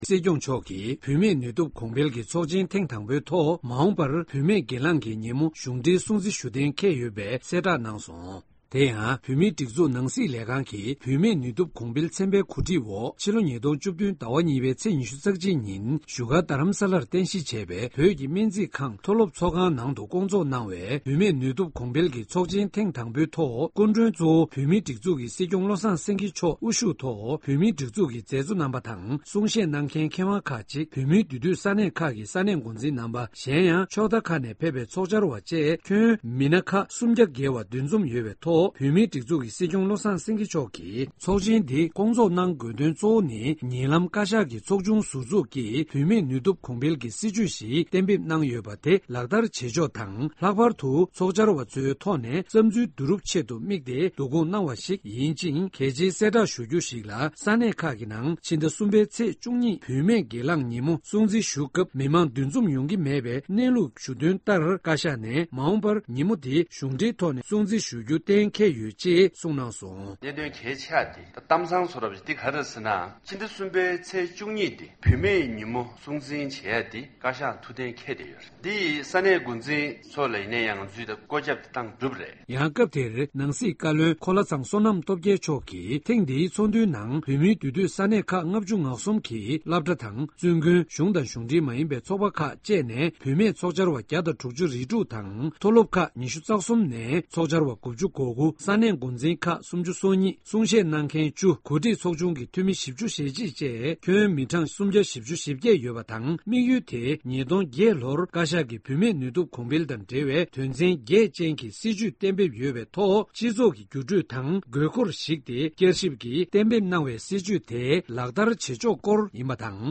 གནས་ཚུལ་ལ་གསན་རོགས་གནང་།